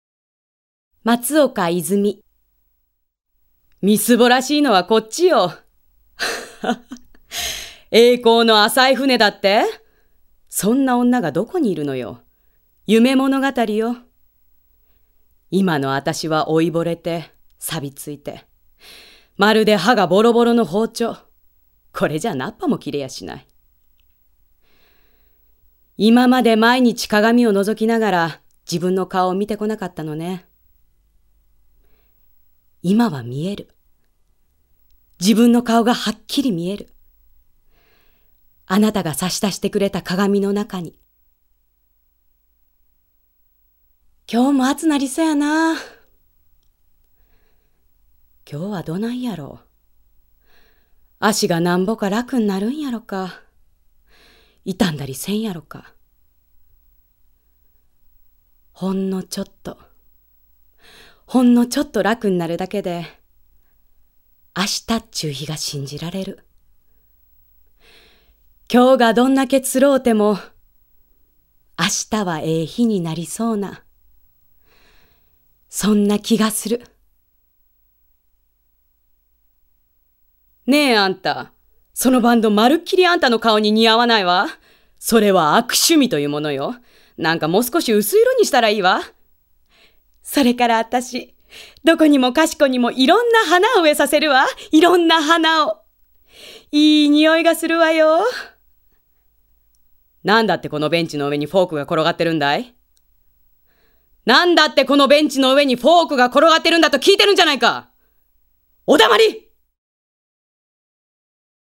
ボイスサンプルはこちら↓
ボイスサンプル